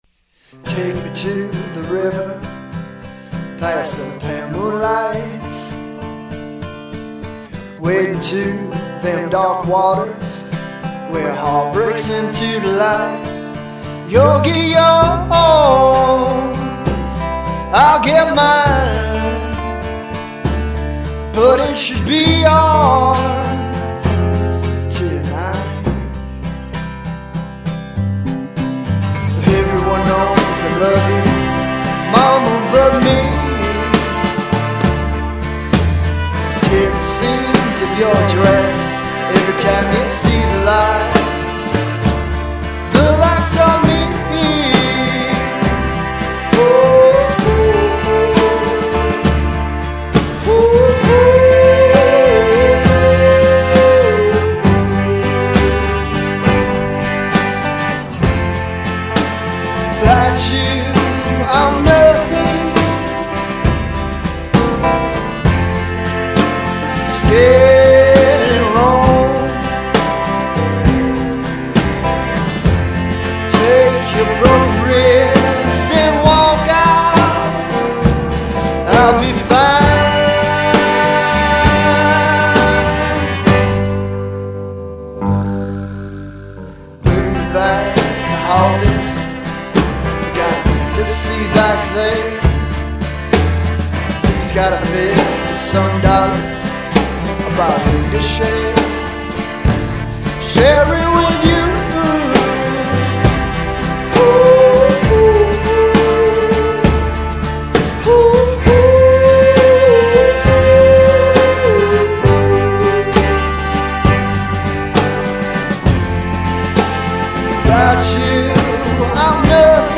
I didn't know what to expect from Minneapolis duo
Regional Mexican/Soul/Pop